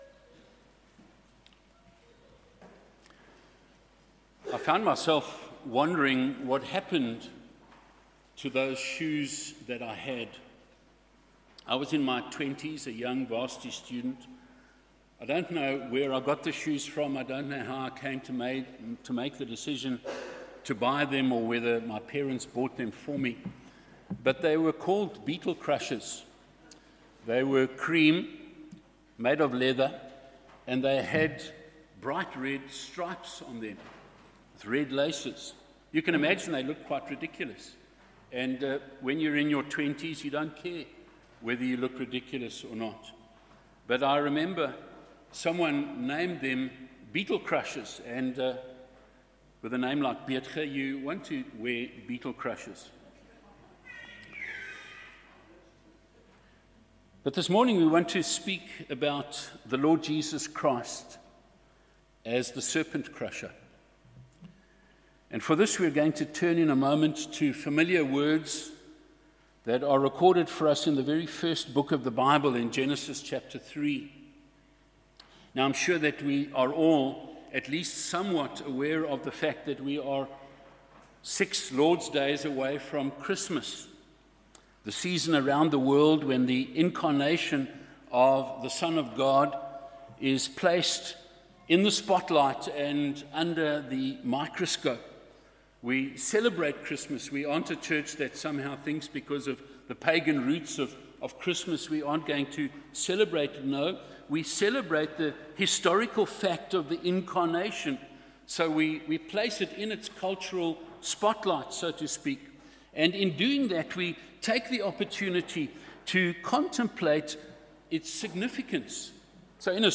Sermons under misc. are not part of a specific expositional or topical series.